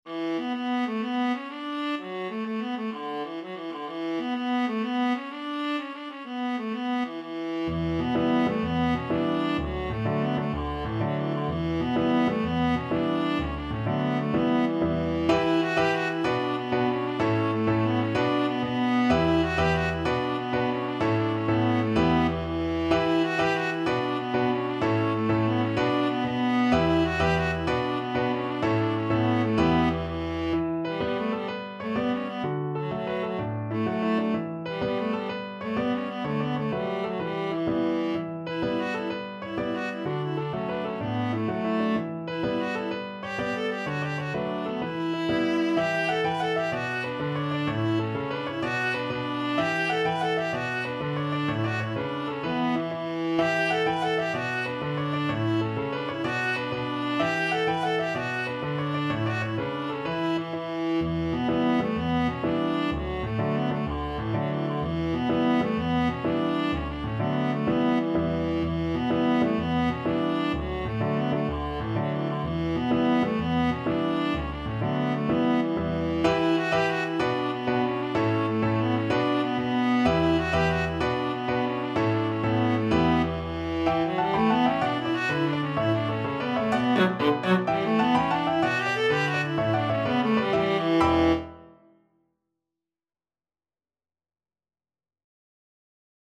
Viola
Traditional Music of unknown author.
B minor (Sounding Pitch) (View more B minor Music for Viola )
Fast .=c.126
12/8 (View more 12/8 Music)
Irish